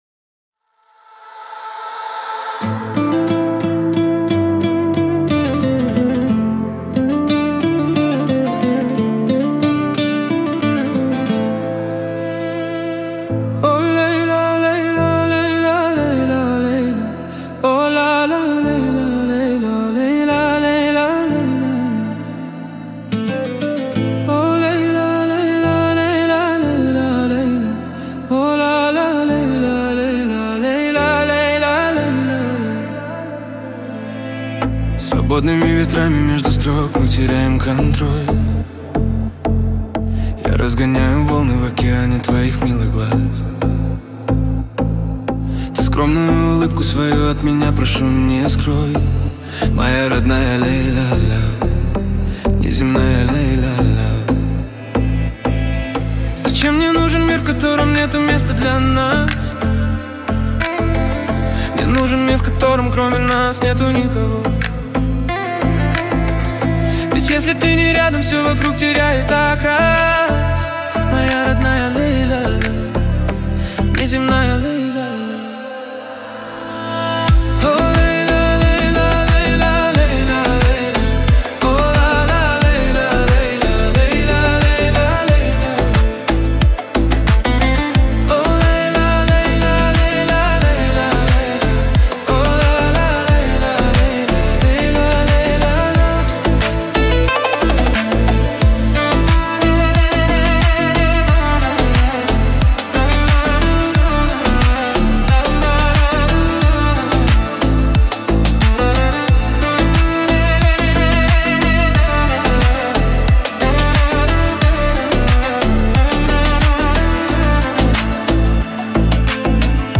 Качество: 320 kbps, stereo
Ремиксы